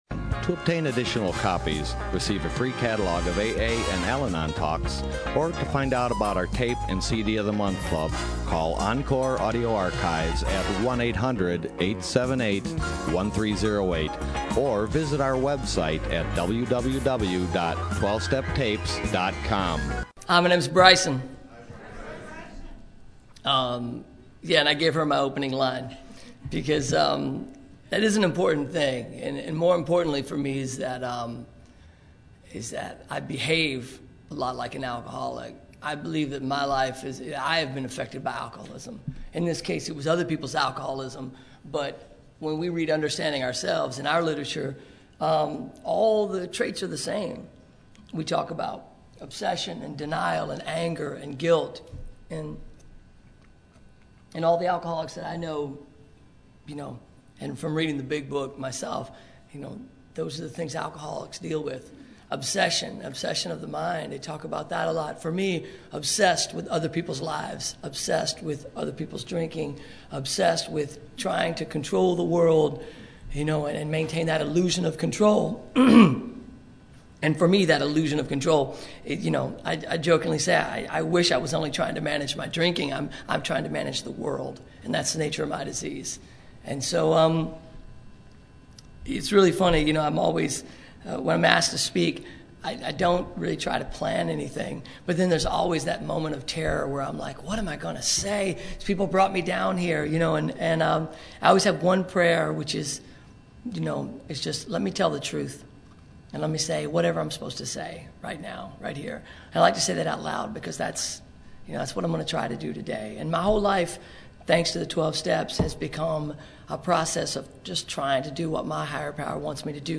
Orange County AA Convention 2012